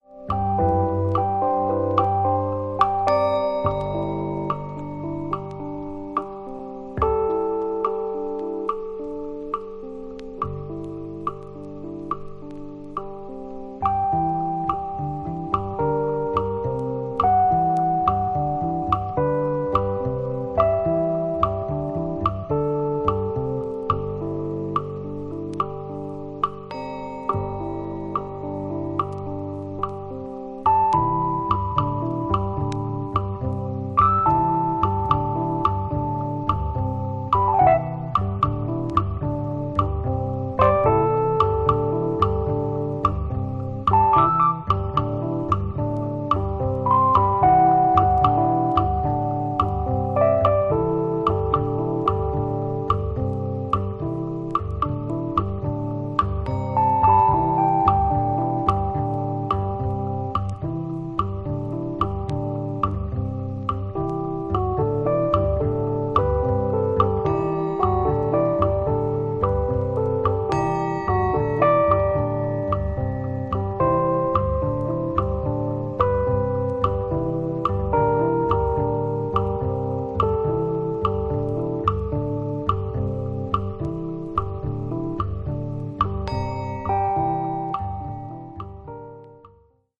穏やかでメロウなインスト・ナンバー